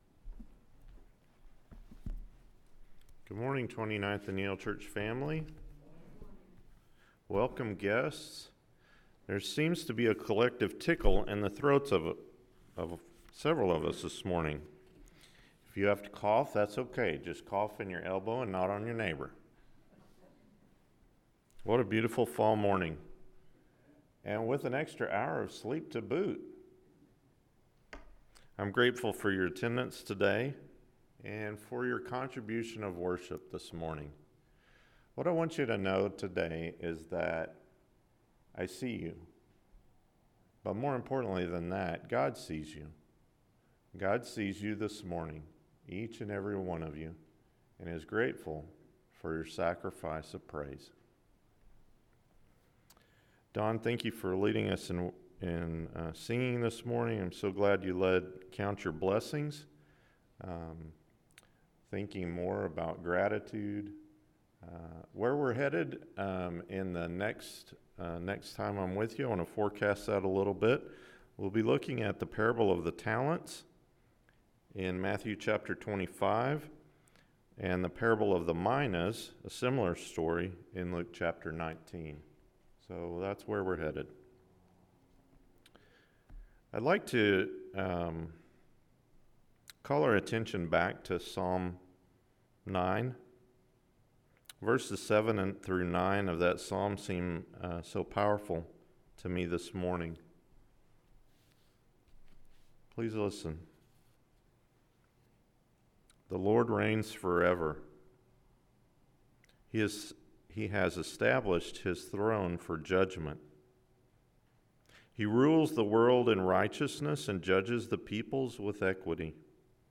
Kingdom Stories: The Weeds & The Net Sermon – Matthew 13:24-30, 36-43, 47-50 – Sermon — Midtown Church of Christ
KingdomStories-TheWeedsAndTheNetSermonAM.mp3